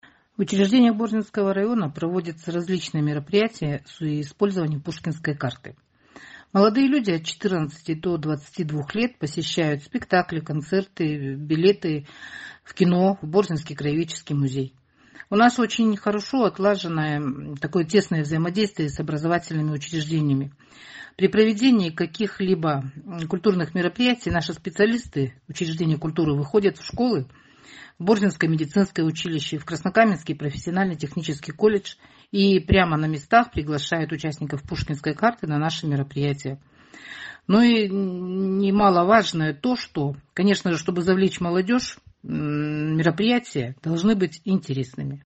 О принципах работы с Пушкинской картой в Борзинском районе рассказывает председатель комитета культуры по АМР «Борзинский район» Татьяна Боровых.